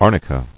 [ar·ni·ca]